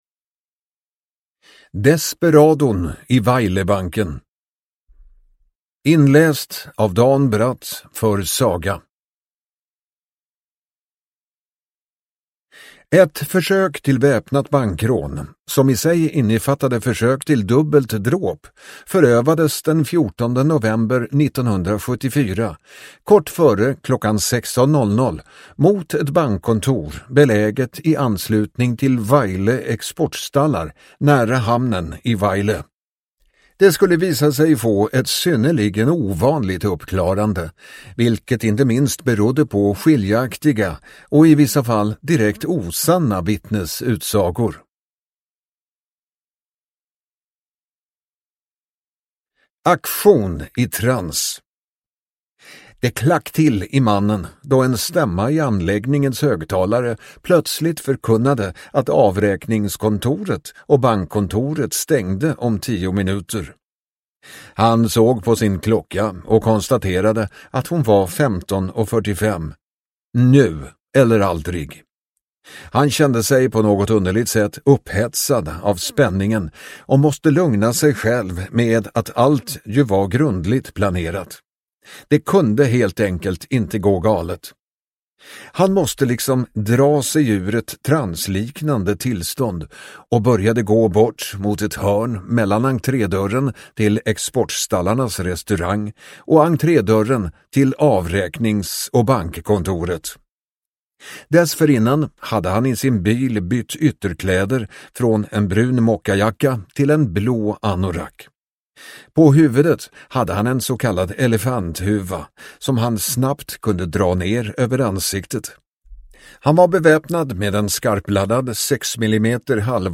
Desperadon i Vejlebanken (ljudbok) av Diverse